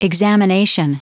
Prononciation du mot examination en anglais (fichier audio)
Prononciation du mot : examination